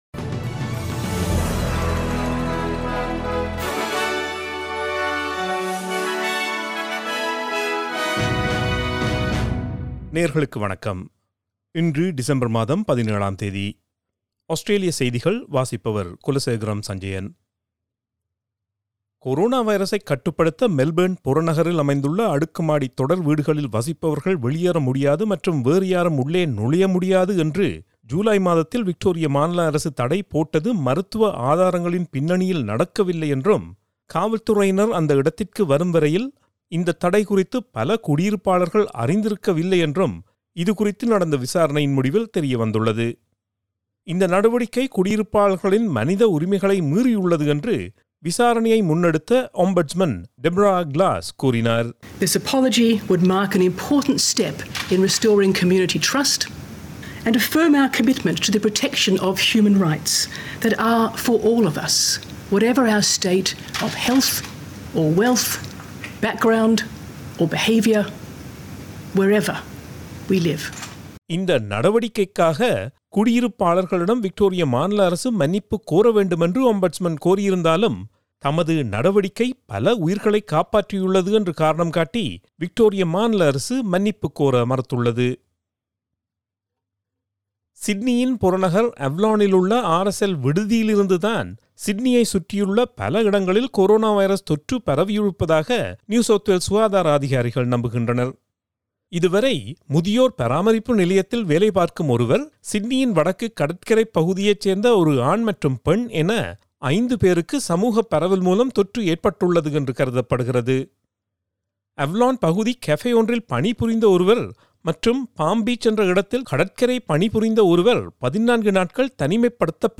Australian news bulletin for Thursday 17 December 2020.